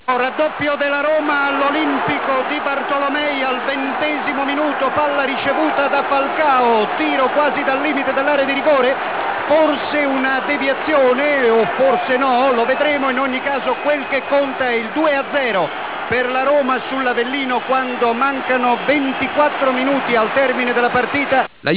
La cronaca del gol di Agostino